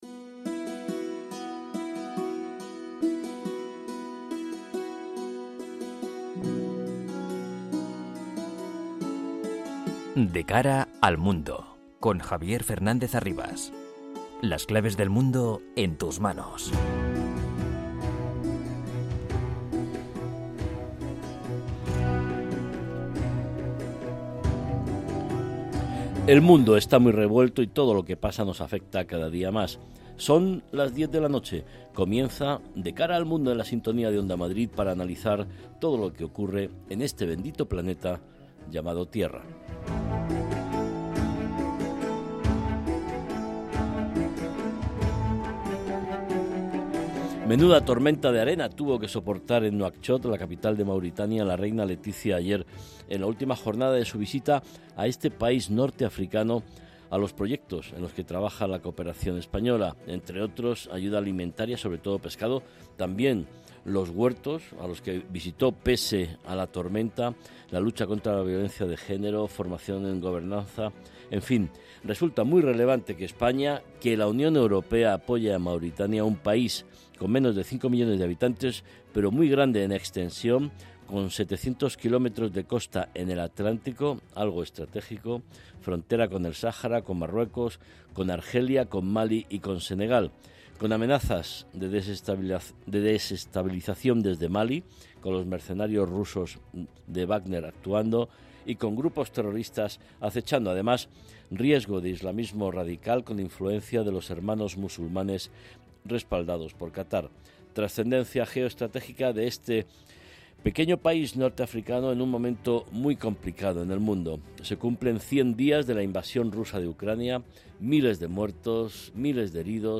entrevistas a expertos y un panel completo de analistas.